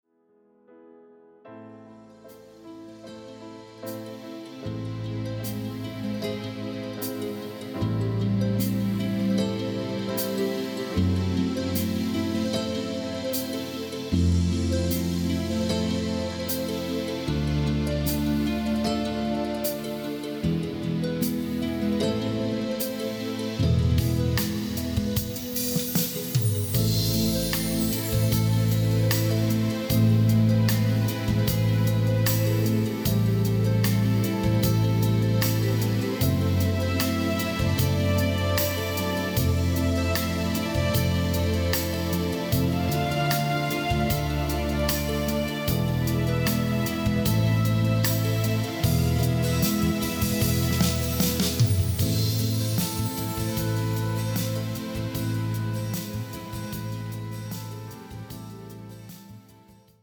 Kontemplation in 4 Sätzen